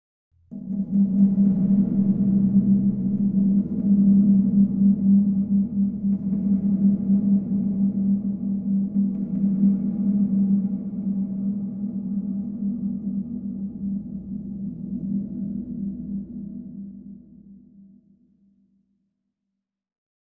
SFX
Nightmare ambiences Demo